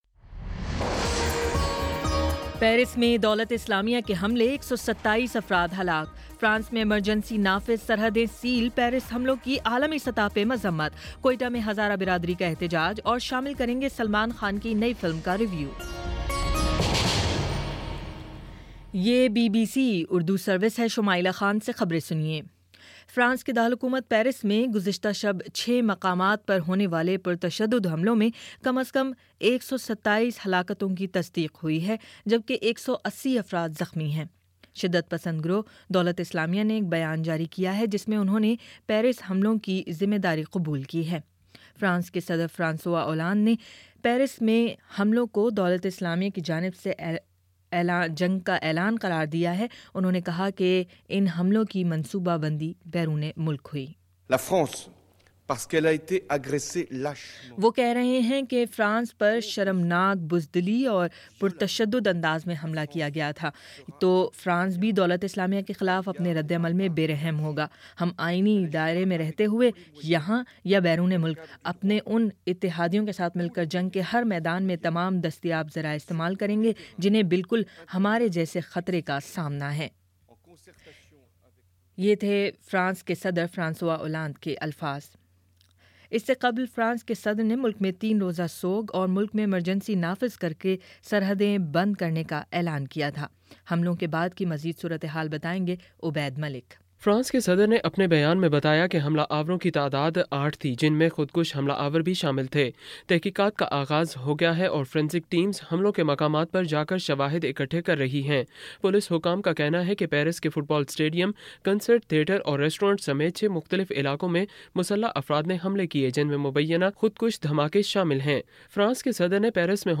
نومبر 14 : شام پانچ بجے کا نیوز بُلیٹن